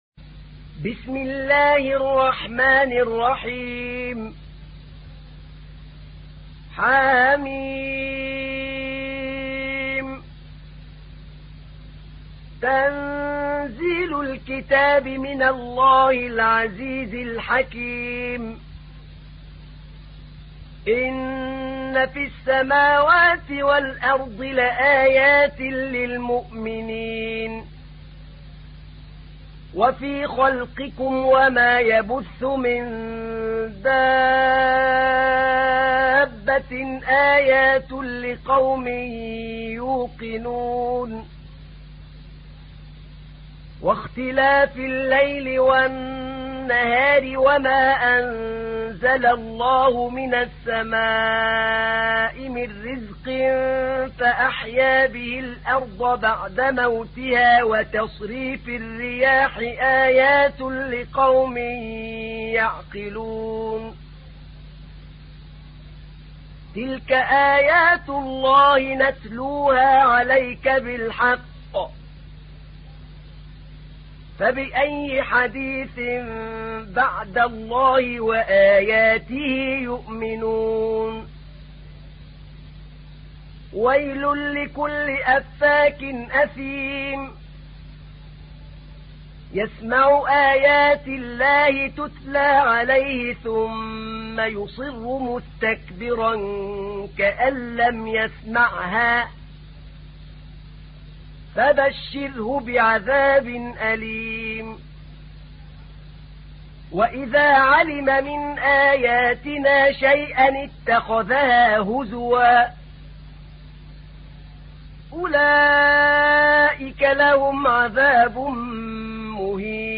تحميل : 45. سورة الجاثية / القارئ أحمد نعينع / القرآن الكريم / موقع يا حسين